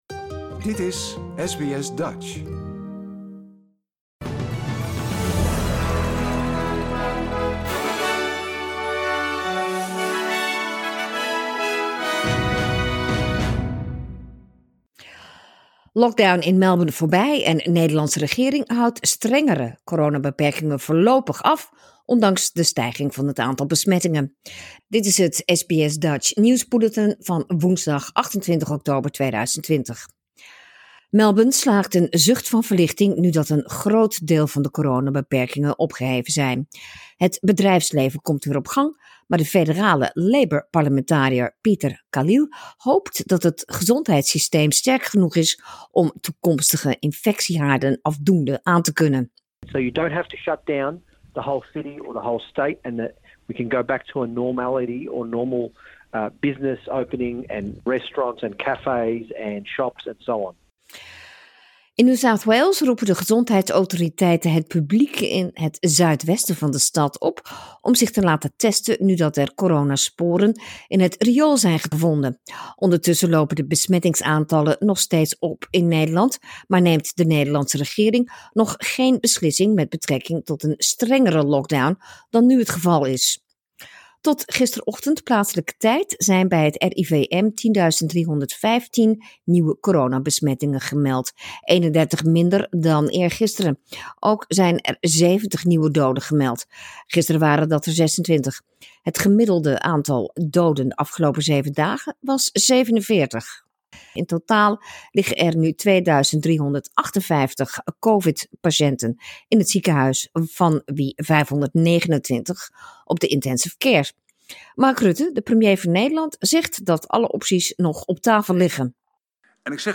Nederlands/Australisch SBS Dutch nieuwsbulletin woensdag 28 oktober 2020